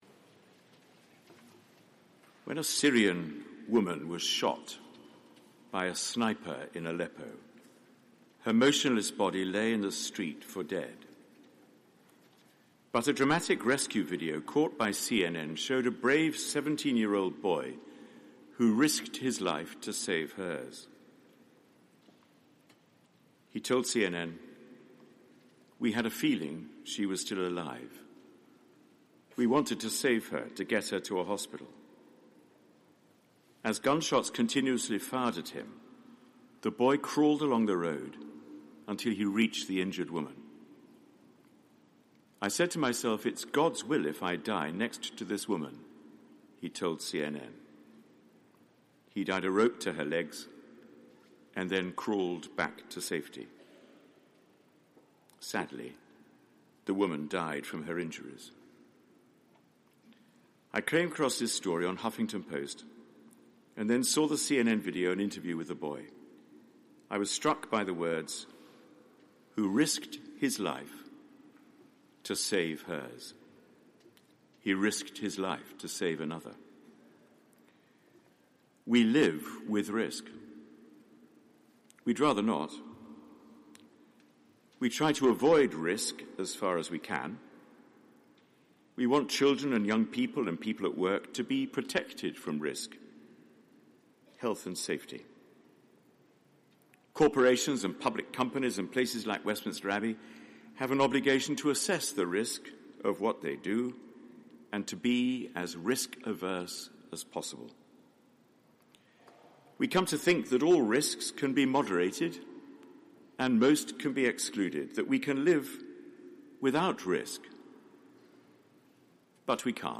Sermon given at Sung Eucharist on All Saints' Day 2013
The Very Reverend Dr John Hall, Dean of Westminster. Today, All Saints’ Day, the Church remembers and celebrates countless acts of heroism and heroic lives. The stories of the saints remind us of people without number willing to dedicate their lives to the service of God and of their fellow human beings.